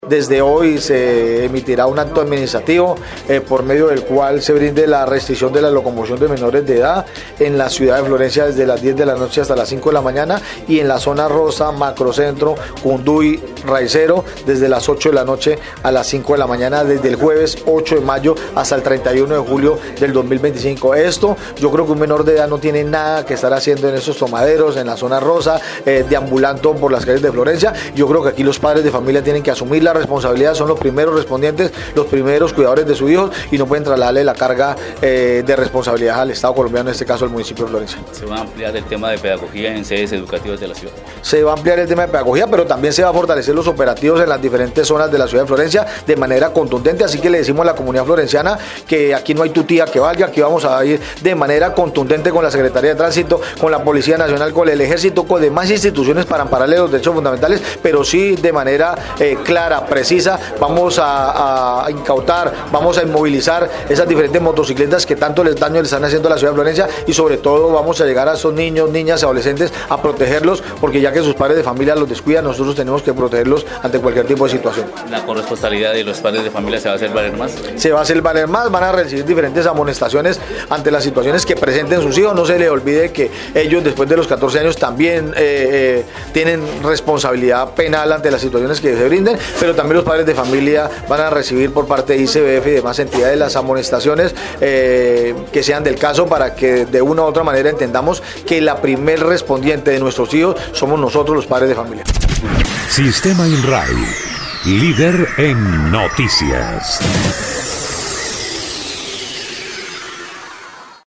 El secretario de gobierno municipal, Carlos Mora Trujillo, agregó que las acciones también tendrán un componente preventivo y se adelantarán en sedes escolares, aplicando una mayor severidad para los padres de familia de los menores que cometa dichas infracciones.